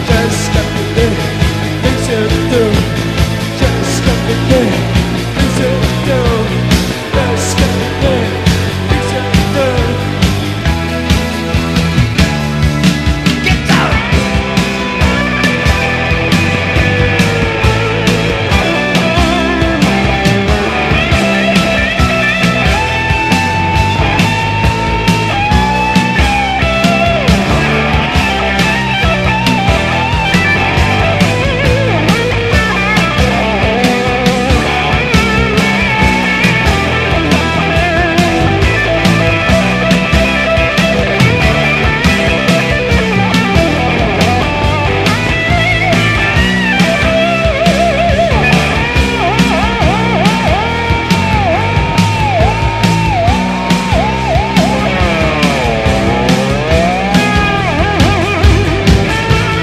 80年代ジャパニーズ・アヴァン・ニューウェイヴ〜ポスト・プログレの異端！
変拍子リズム、詩的かつ難解な日本語詞が交錯する異形の世界観を展開した和製キング・クリムゾンなサウンドが見事！